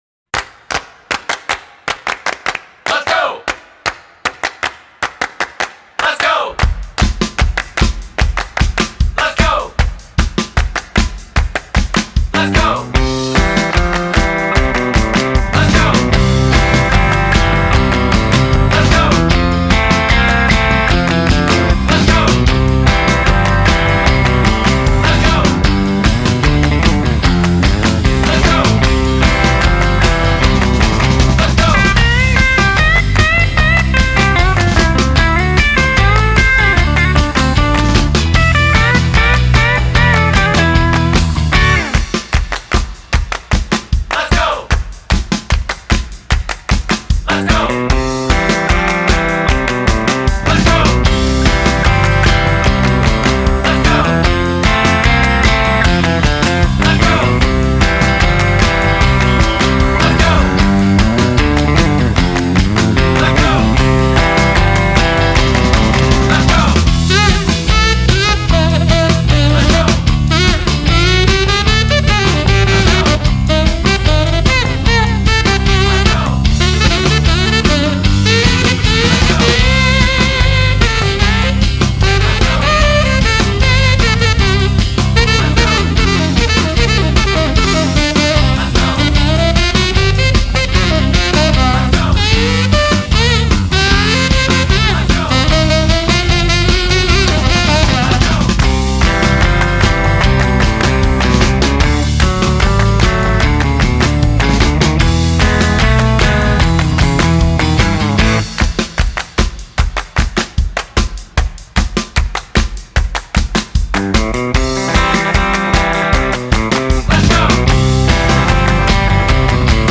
an American instrumental rock band
Genre: Instrumental